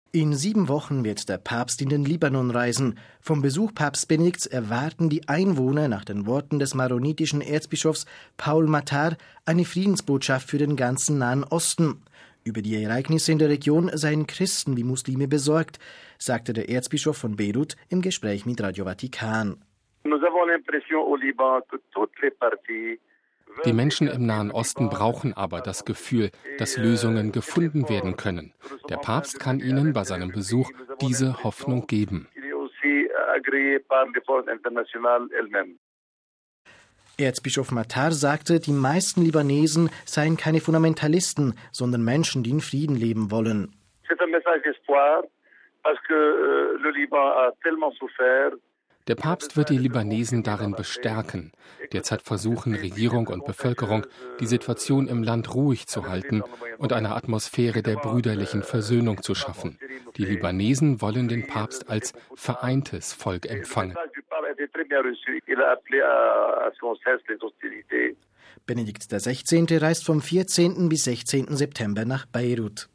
Vom Besuch Papst Benedikts XVI. im Libanon erwarten die Einwohner nach den Worten des maronitischen Erzbischofs Paul Matar eine Friedensbotschaft für den ganzen Nahen Osten. Über die Ereignisse in der Region seien Christen wie Muslime besorgt, sagte der Erzbischof von Beirut im Gespräch mit Radio Vatikan.